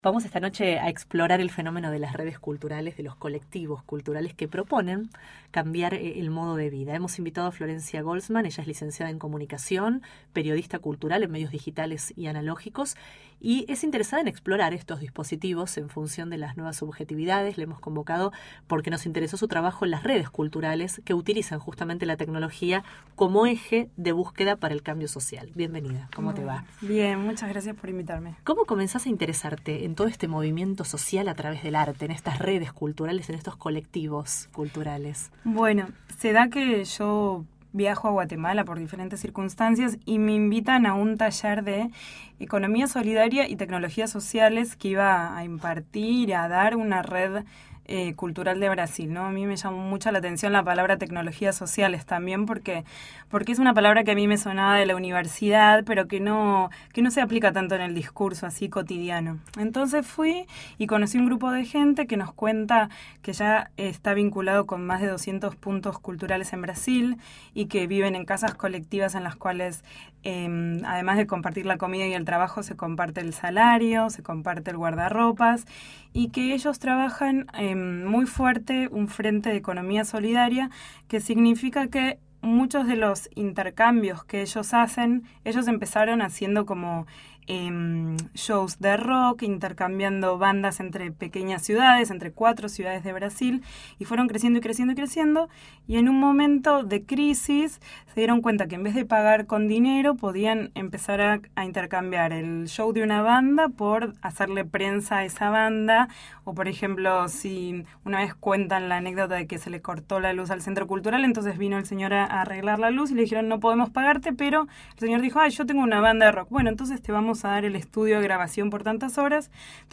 Audio de entrevista El Explorador Cultural